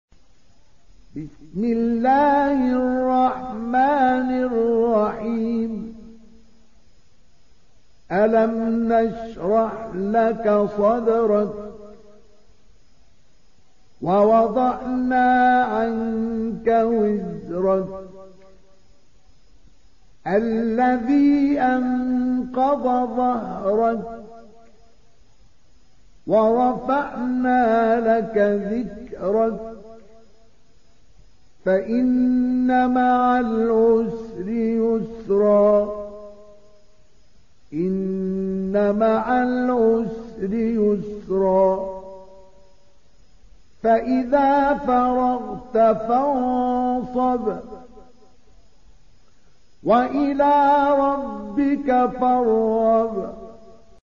تحميل سورة الشرح mp3 بصوت مصطفى إسماعيل برواية حفص عن عاصم, تحميل استماع القرآن الكريم على الجوال mp3 كاملا بروابط مباشرة وسريعة